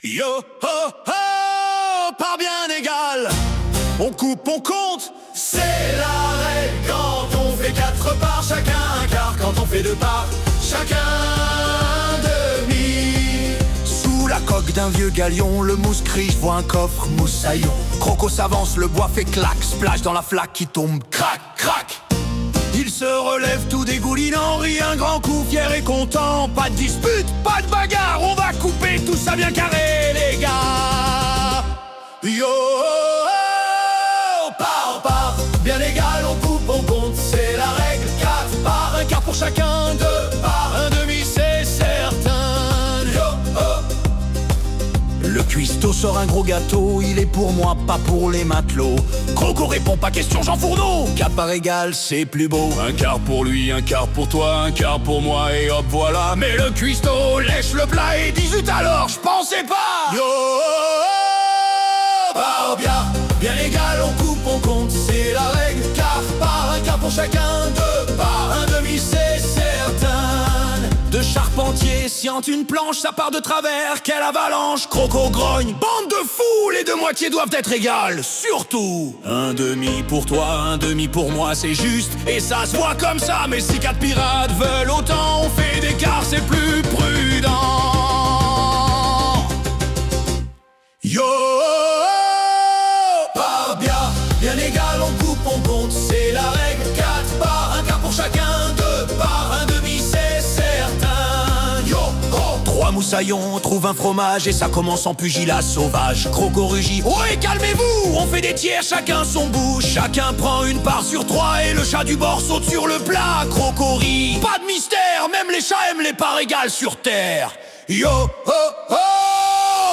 Des chansons pour apprendre ! Et pour le plaisir !